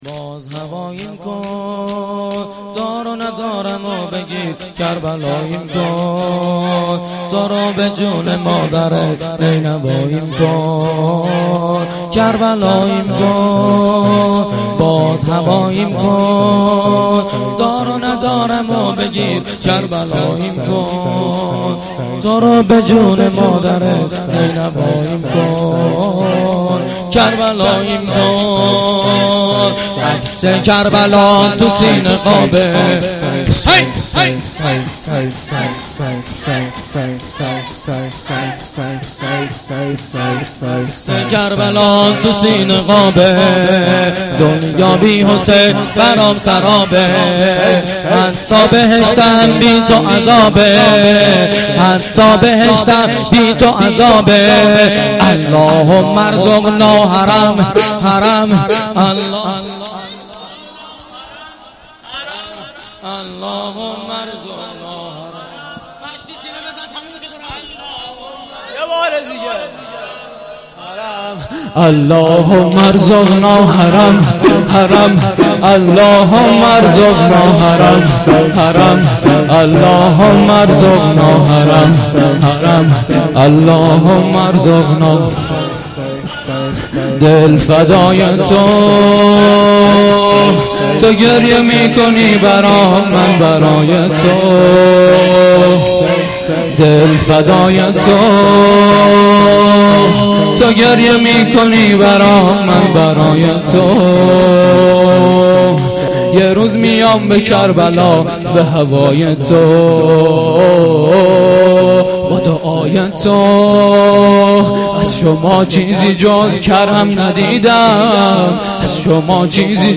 گزارش صوتی جلسه هفتگی2دیماه
شور2